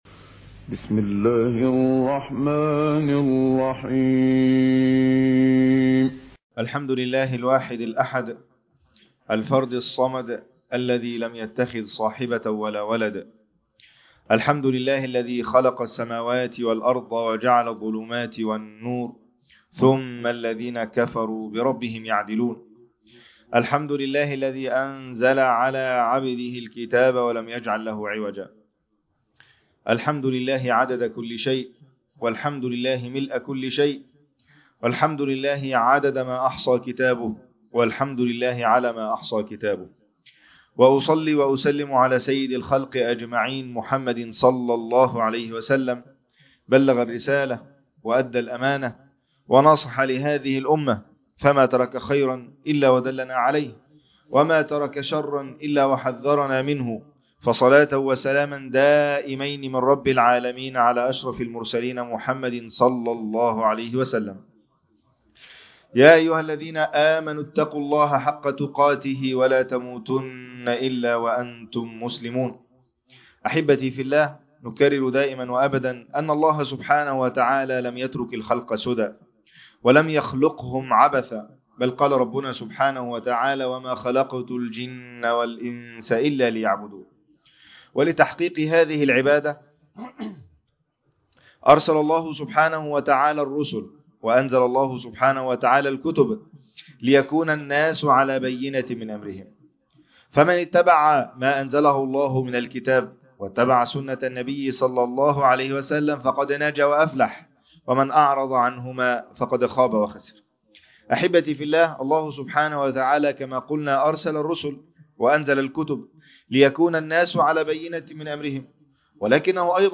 عنوان المادة إنه القرآن - كل نفس ذائقة الموت _ خطبة جمعة تاريخ التحميل الثلاثاء 27 يناير 2026 مـ حجم المادة 12.39 ميجا بايت عدد الزيارات 51 زيارة عدد مرات الحفظ 20 مرة إستماع المادة حفظ المادة اضف تعليقك أرسل لصديق